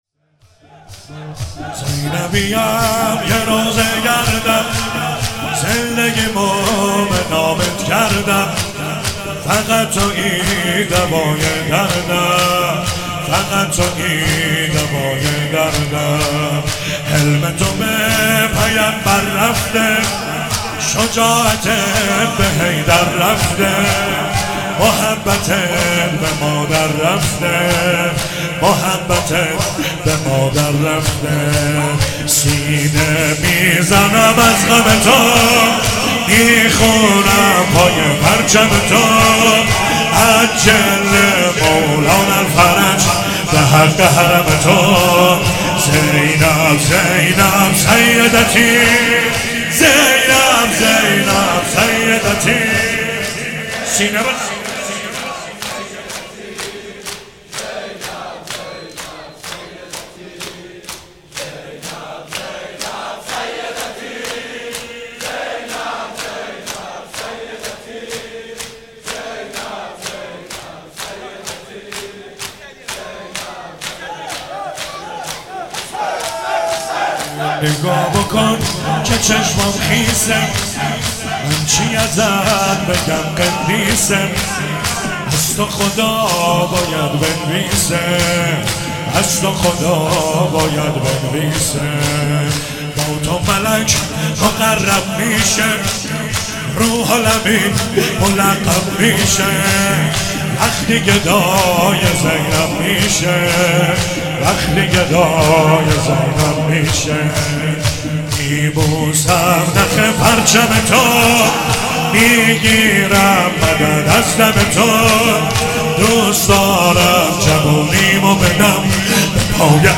شهادت حضرت زینب (س) 97 - شور - زینبیم یه روضه گردم
شهادت حضرت زینب سلام الله علیها